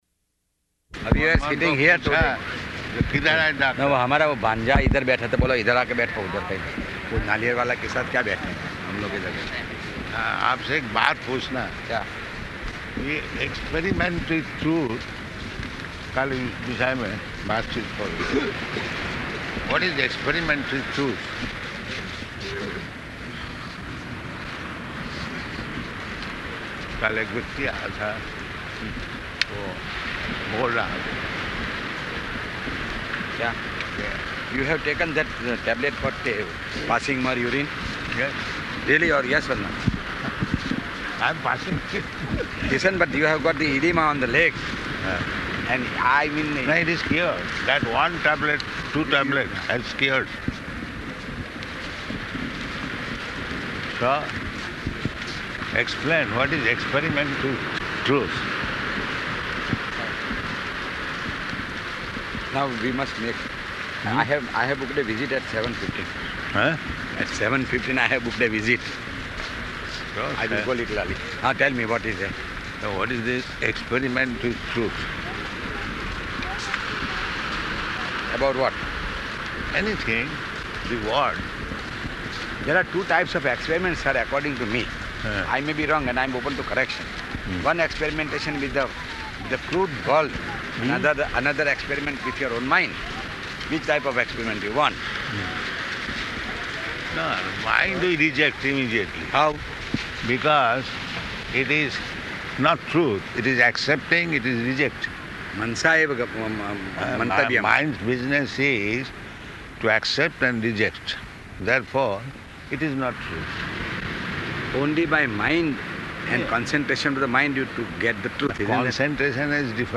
-- Type: Walk Dated: December 24th 1975 Location: Bombay Audio file